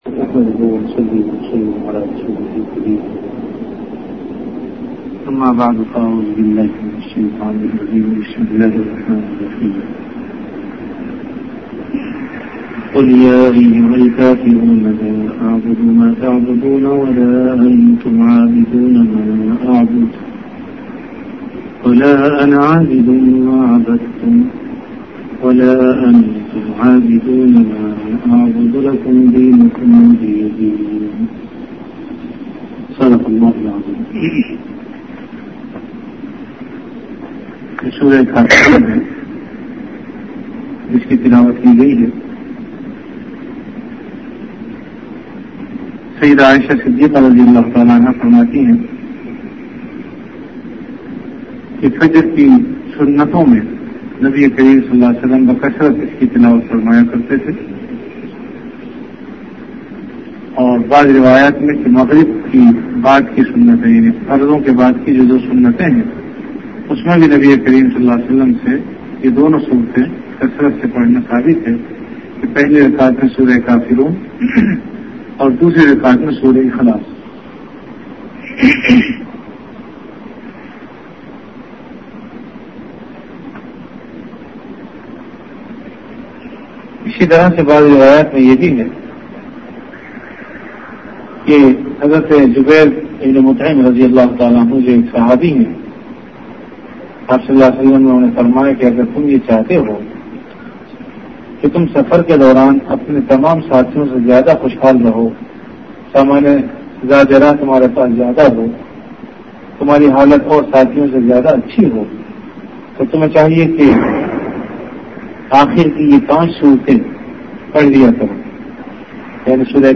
Dars-e-quran · Jamia Masjid Bait-ul-Mukkaram, Karachi
Event / Time After Isha Prayer